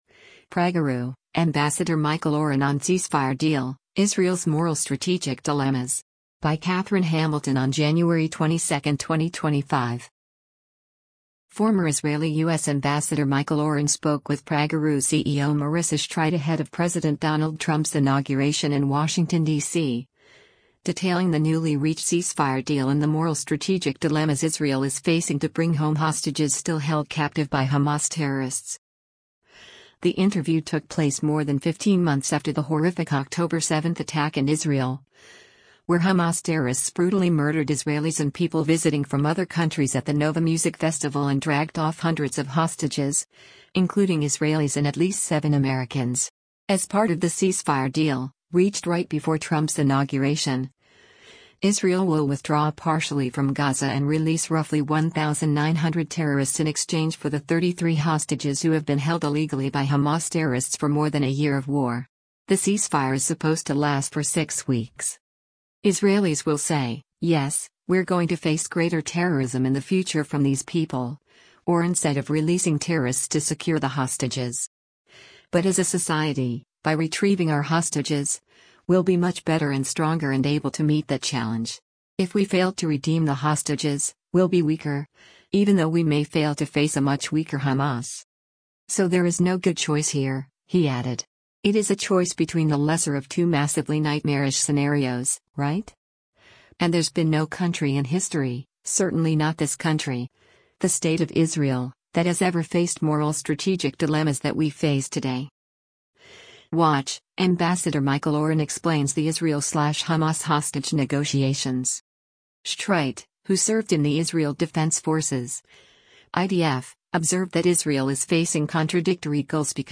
The interview took place more than 15 months after the horrific October 7 attack in Israel, where Hamas terrorists brutally murdered Israelis and people visiting from other countries at the Nova music festival and dragged off hundreds of hostages, including Israelis and at least seven Americans.